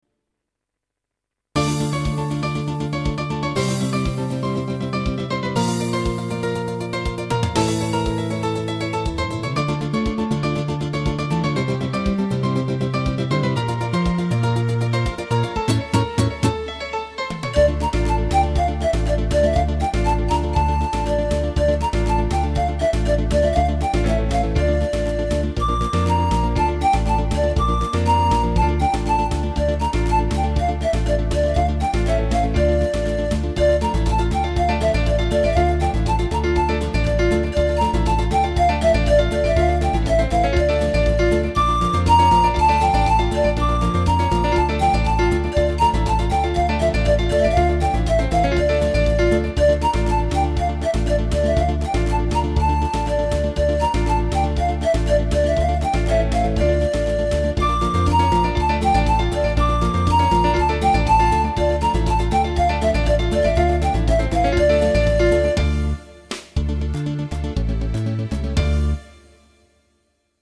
Basi musicali originali